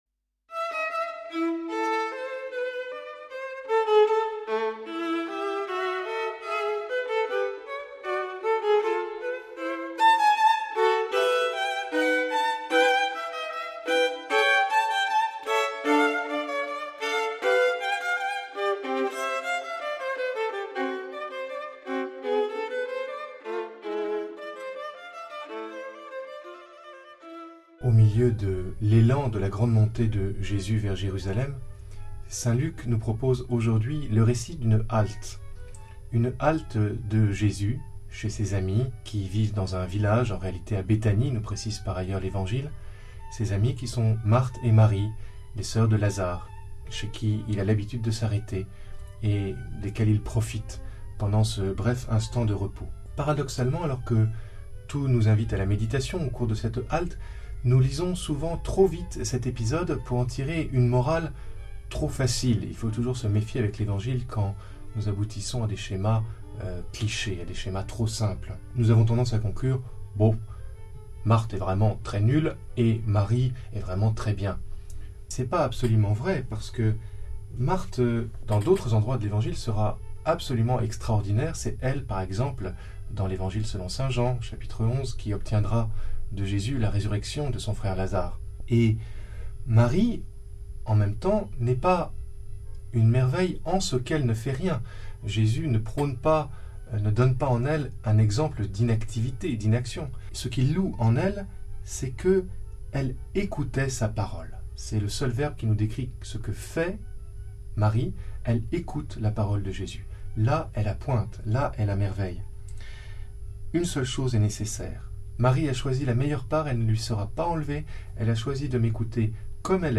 le commentaire de l'Evangile